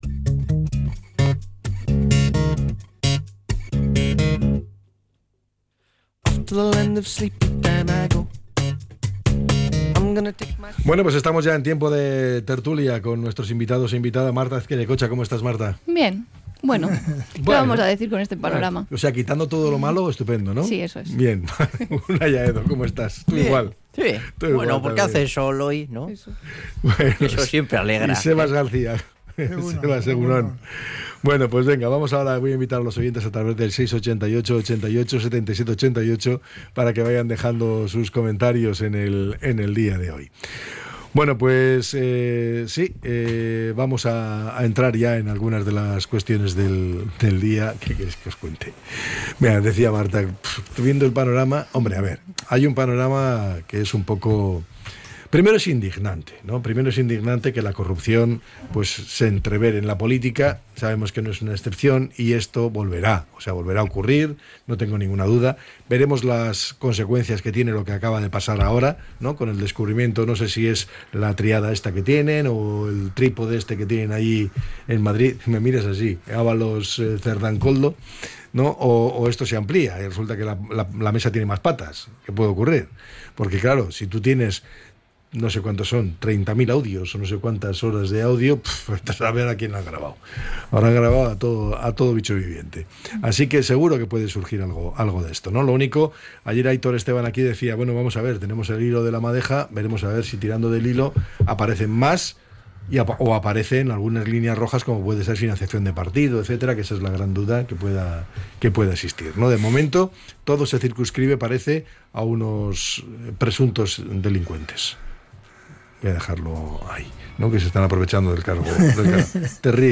La tertulia 18-06-25.